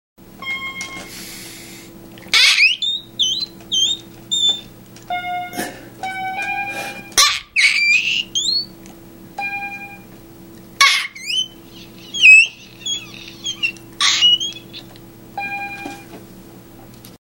highestnote.mp3